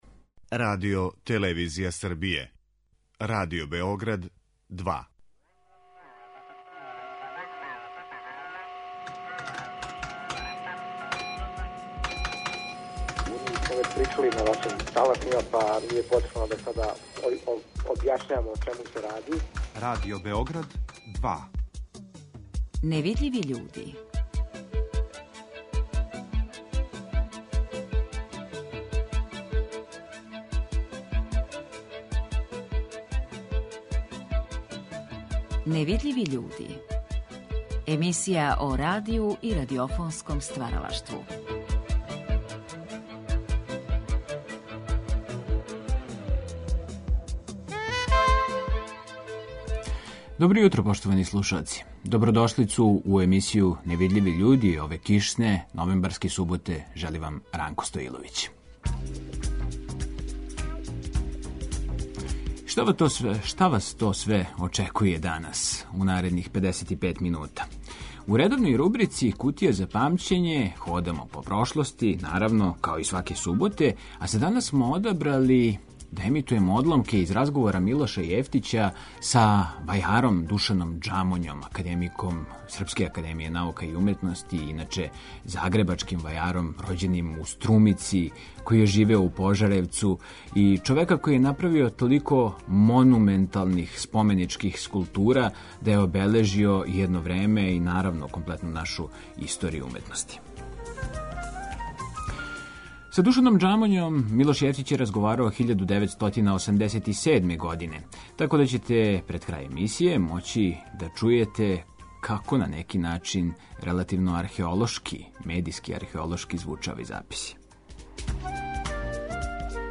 Ови разговори вођени су 1987. године за циклус емисија „Гост Другог програма".